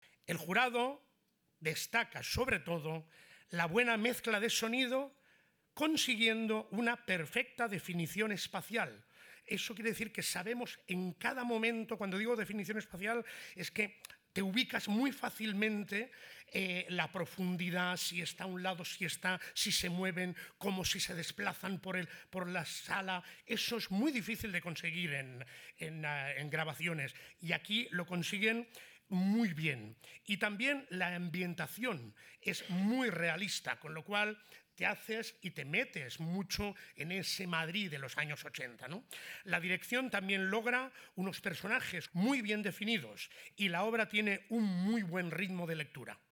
El jurado destaca “la buena mezcla de sonido consiguiendo una perfecta definición espacial y la ambientación realista.